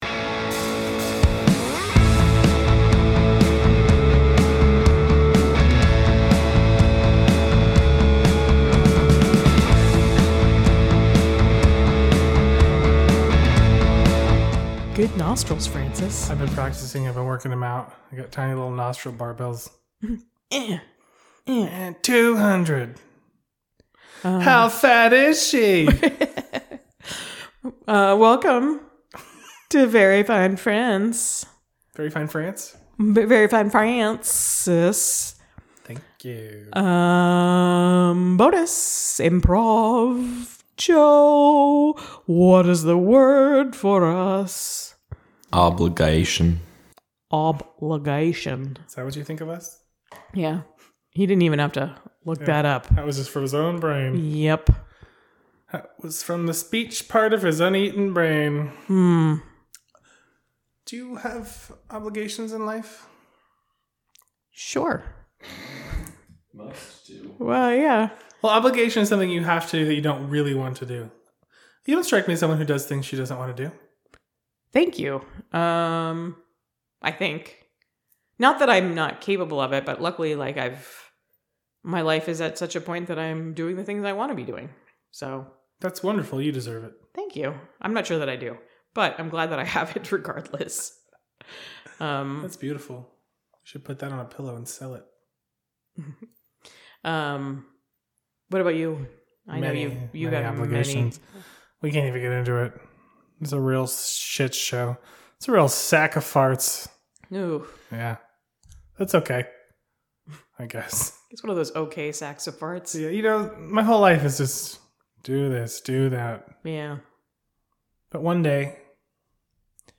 do improv!